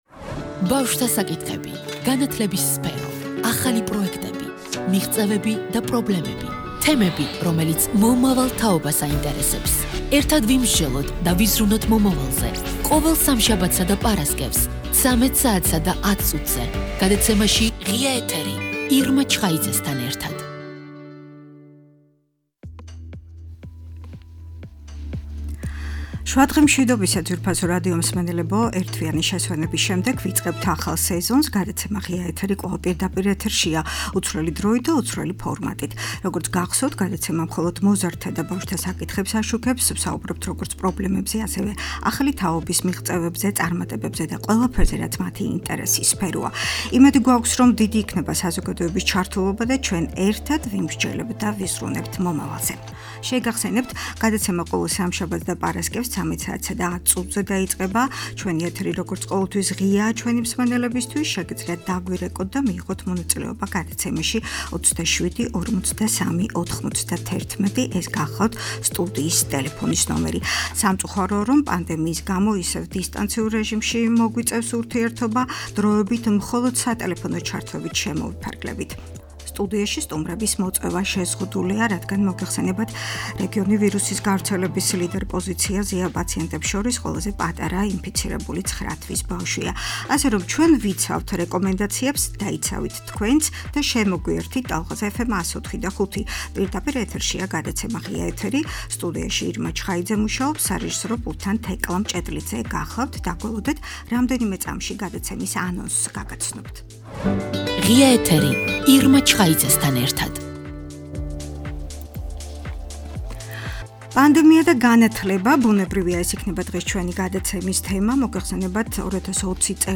,,ღია ეთერის’’ ახალი სეზონი- გადაცემის სტუმარი -აჭარის განათლების, კულტურისა და სპორტის მინისტრი მაია ხაჯიშვილი..პანდემია და განათლება ,მოსწავლეთა გზავნილები - ღია ბარათი ,,კორონას’’ ..